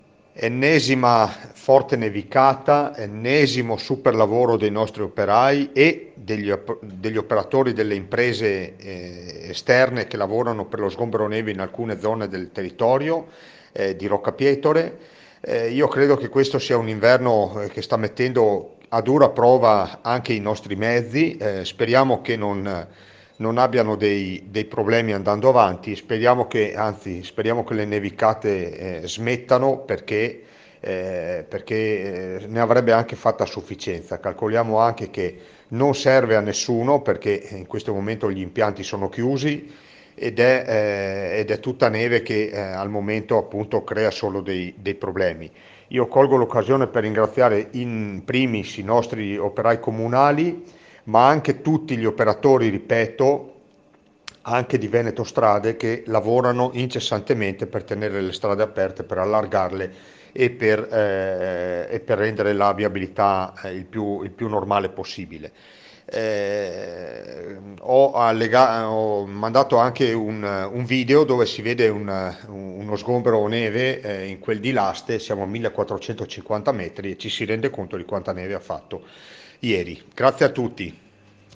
ROCCA PIETORE Dopo la nevicata delle ultime ore a Rocca Pietore sono entrati in funzione i mezzi per lo sgombero. Ne parla il sindaco di Rocca Pietore Andrea De Bernardin